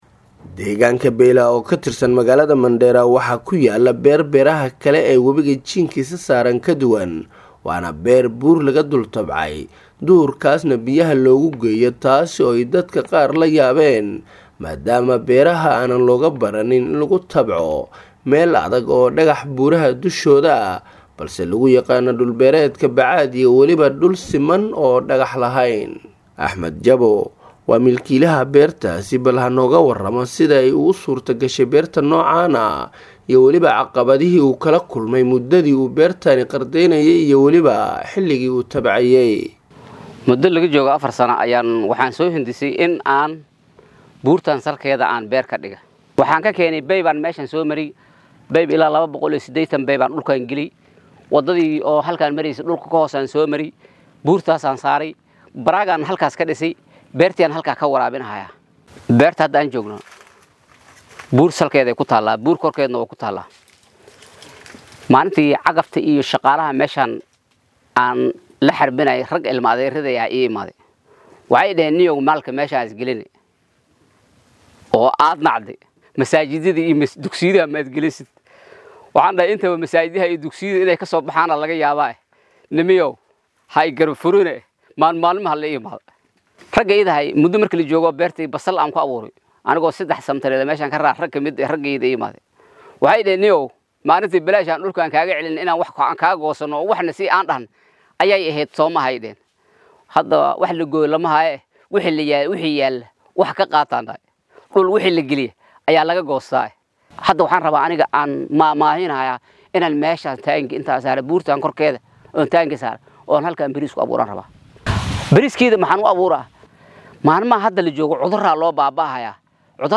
DHAGEYSO:Warbixin:Beer laga tabcay buur dusheed ee Mandera